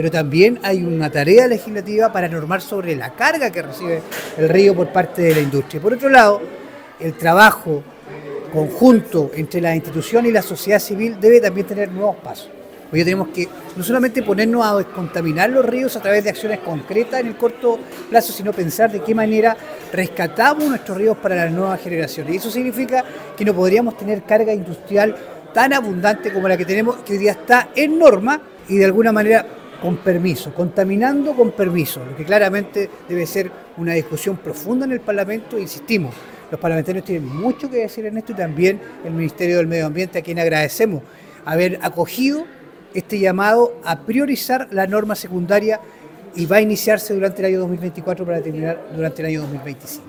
El Consejero Reyes, indicó que existe la necesidad de trabajar legislativamente para limitar la carga de contaminantes en el Río Rahue, principalmente por parte de las industrias locales.